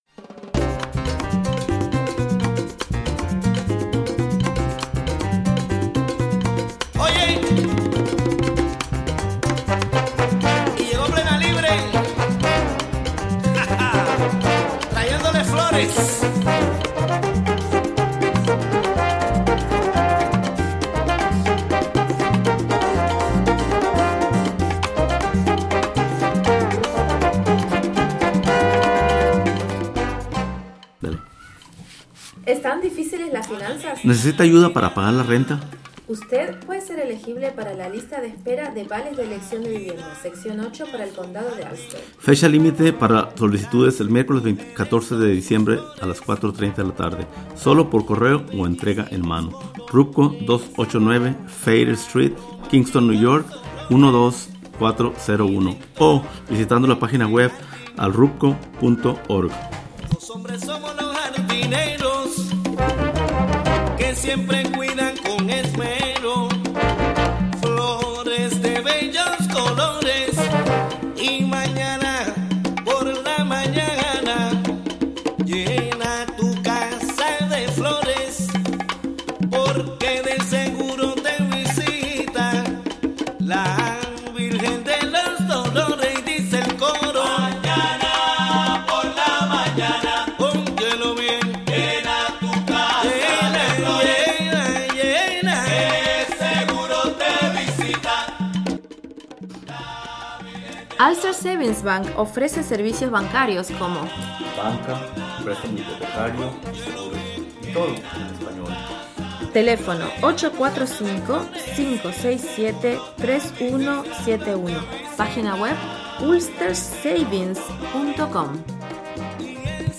9am Un programa imperdible con noticias, entrevistas,...